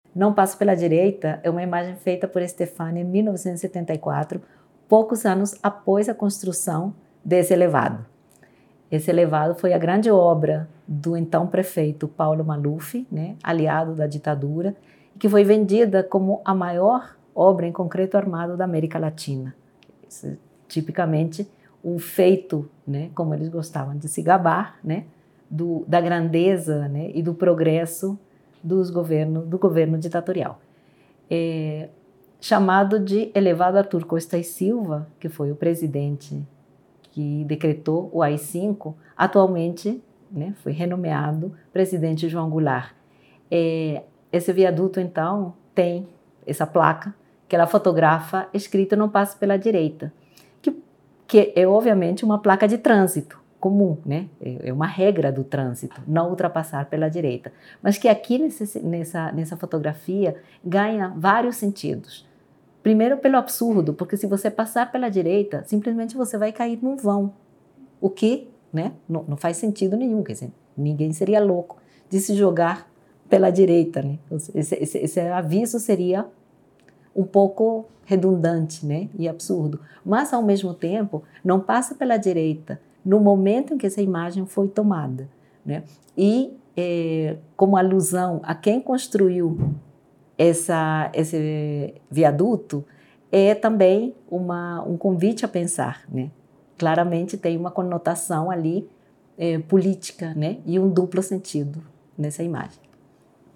Faixa 2 - Comentário da curadoria